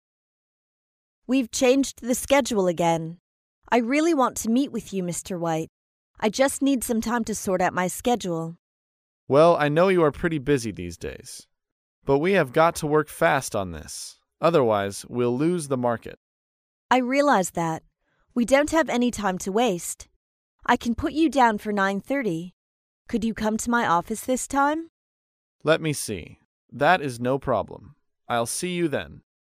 在线英语听力室高频英语口语对话 第68期:更改见面时间(1)的听力文件下载,《高频英语口语对话》栏目包含了日常生活中经常使用的英语情景对话，是学习英语口语，能够帮助英语爱好者在听英语对话的过程中，积累英语口语习语知识，提高英语听说水平，并通过栏目中的中英文字幕和音频MP3文件，提高英语语感。